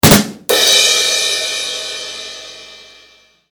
rimshot.mp3